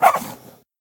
arf.ogg